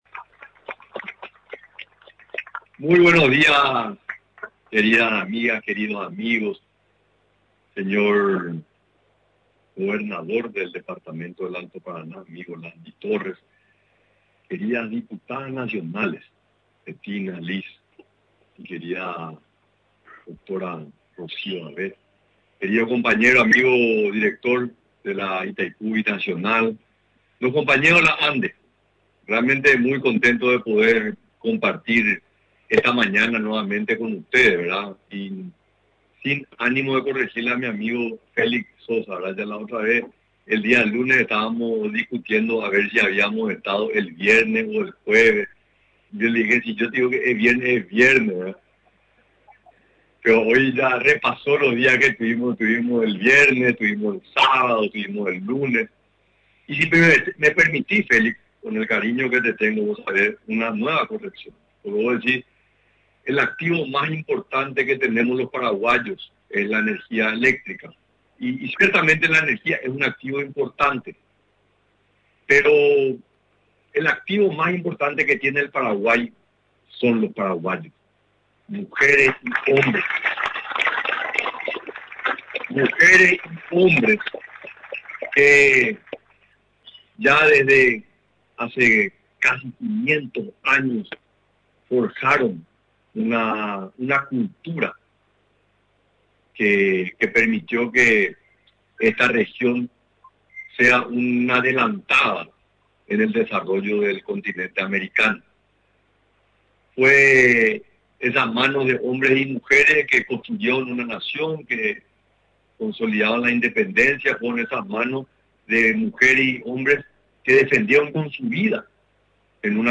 El presidente de la República, Santiago Peña, encabezó este jueves la apertura de la reunión de la Asociación Iberoamericana de Ministerios Públicos destacando que la misma envía un mensaje simbólico contundente al crimen transnacional.
En su discurso, el presidente resaltó que la realización en la triple frontera de la reunión de la Asociación Iberoamericana de Ministerios Públicos y de Ministerios Públicos del Mercosur implica «un gran simbolismo» ya que la zona fue por mucho tiempo considerada sinónimo de criminalidad, delincuencia organizada y oscuridad.